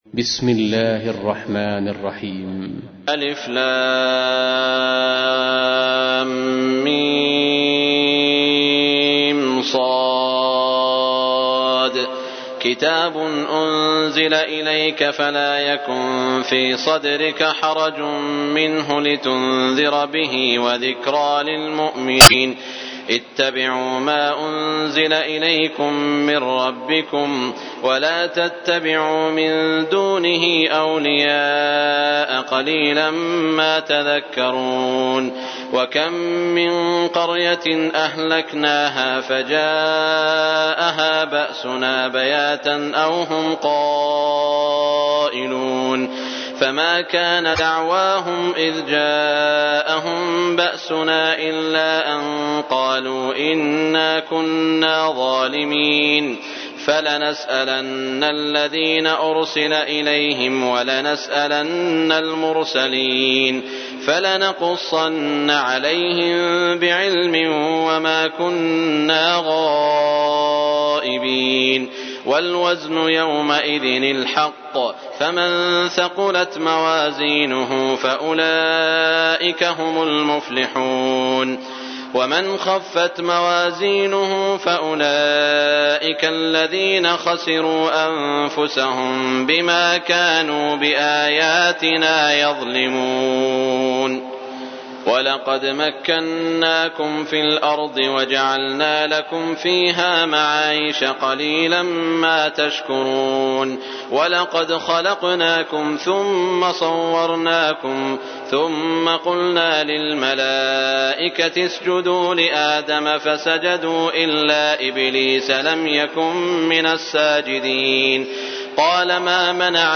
تحميل : 7. سورة الأعراف / القارئ سعود الشريم / القرآن الكريم / موقع يا حسين